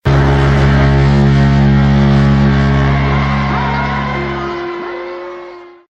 BIG BOAT FOGHORN.mp3
A big ship foghorn blaring, moving towards new seas.
big_boat_foghorn_qfo.ogg